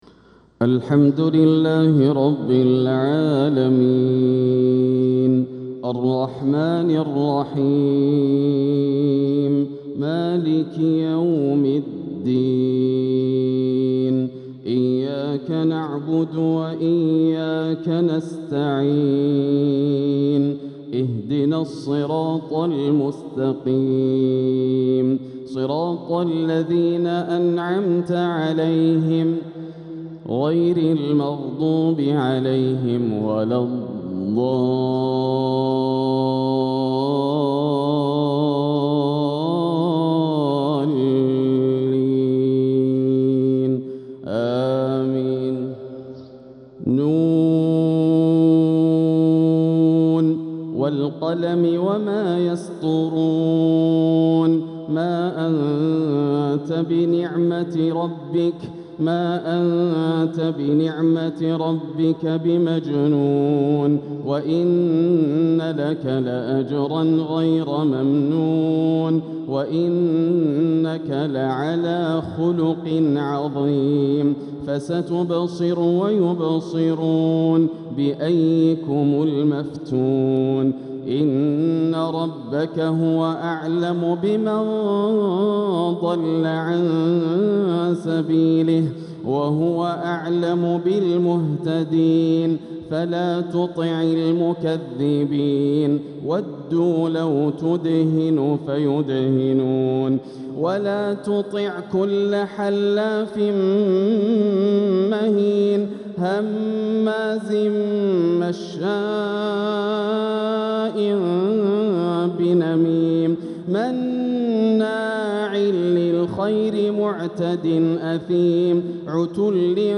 ترتيل جميل لسورة القلم ـ فجر الخميس 5-7-1447هـ > عام 1447 > الفروض - تلاوات ياسر الدوسري